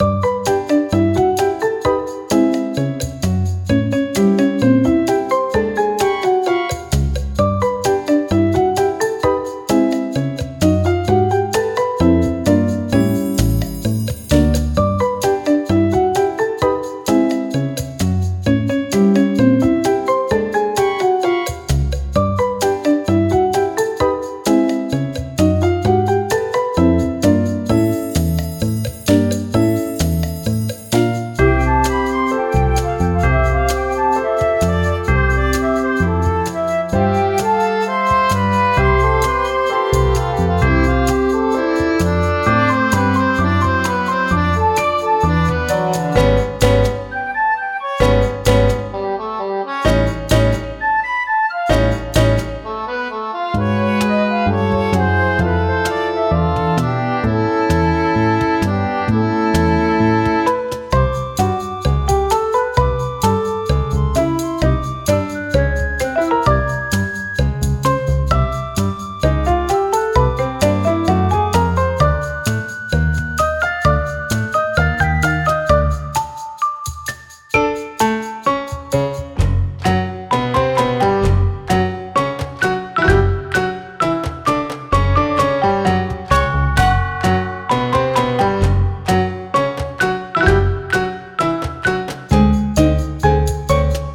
明るい楽曲
【イメージ】おとぼけさん、へんてこ など